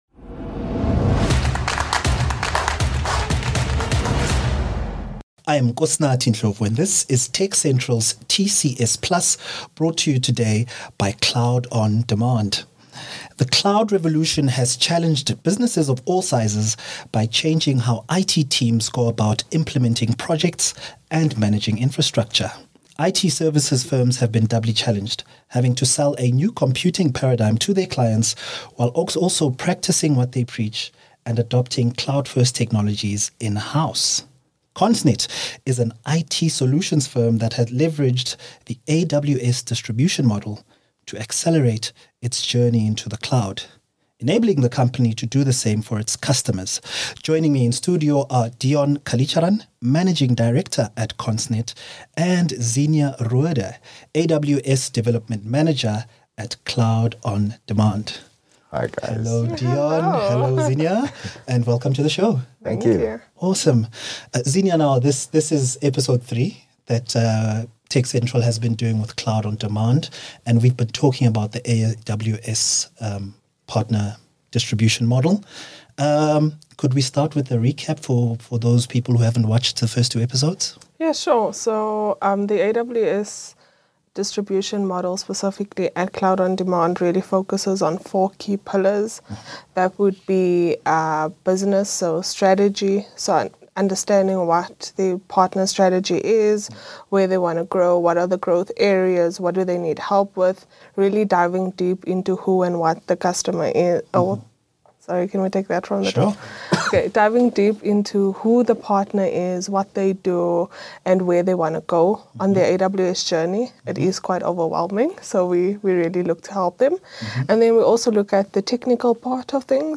Don’t miss this informative conversation!